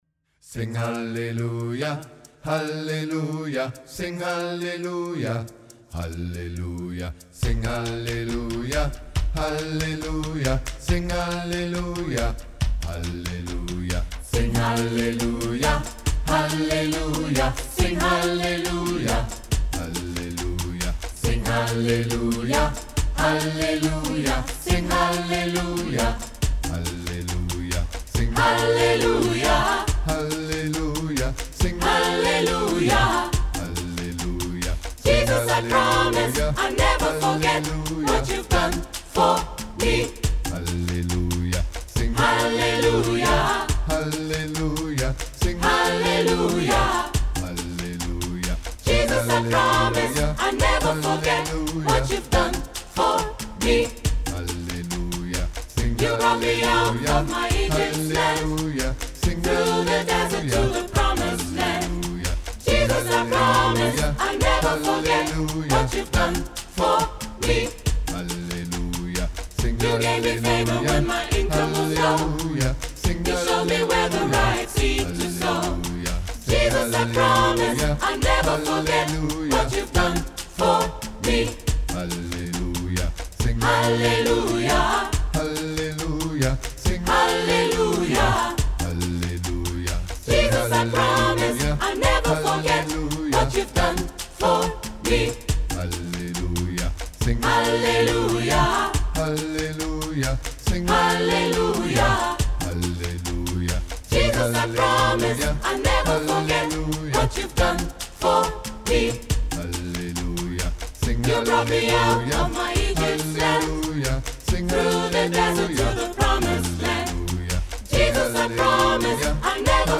• SATB a cappella